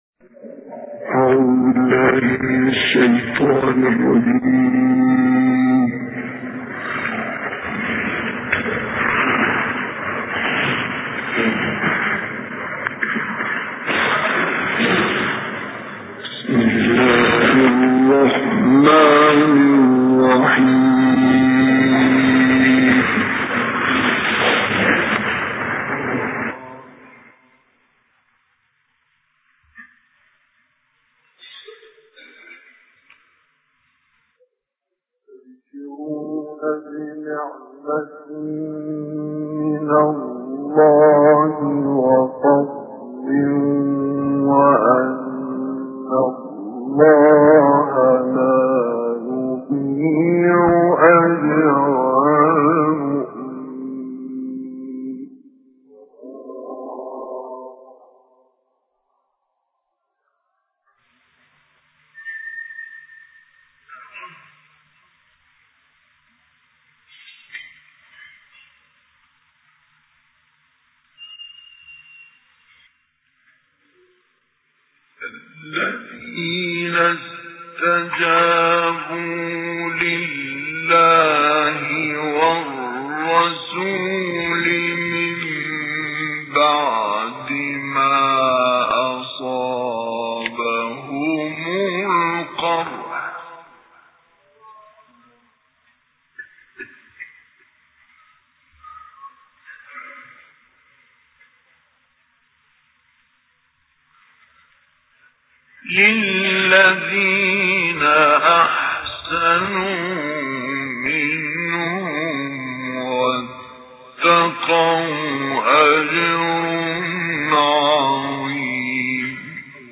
گروه فعالیت‌های قرآنی: تلاوت دلنشین آیاتی از سوره آل عمران با صوت مصطفی اسماعیل که در سال 1957 میلادی اجرا شده است، ارائه می‌شود.